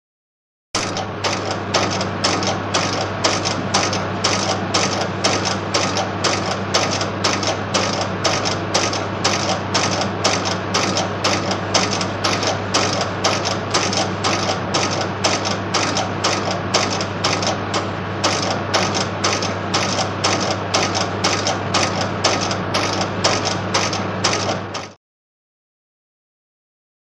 Machine; Machine Running. Constant Clack-clack Could Be A Printing Machine.